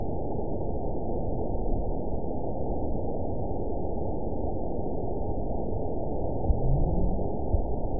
event 920382 date 03/20/24 time 23:19:56 GMT (1 year, 1 month ago) score 8.49 location TSS-AB04 detected by nrw target species NRW annotations +NRW Spectrogram: Frequency (kHz) vs. Time (s) audio not available .wav